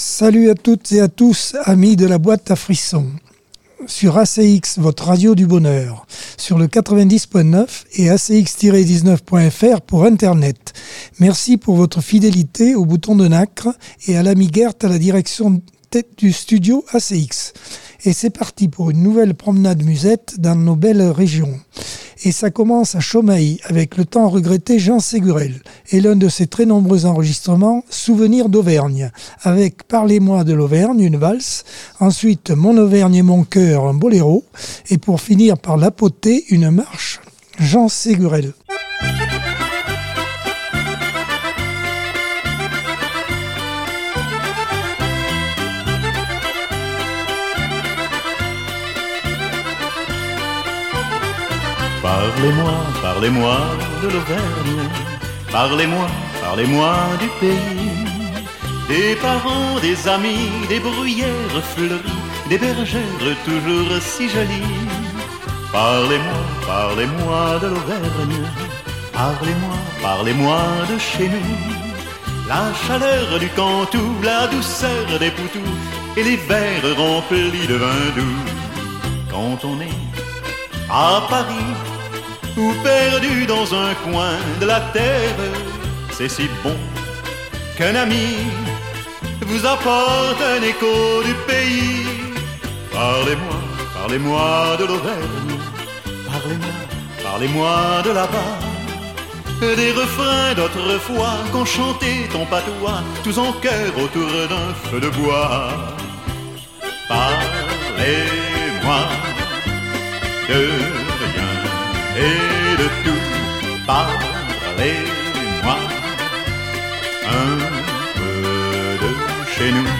Accordeon 2024 sem 36 bloc 1 - Radio ACX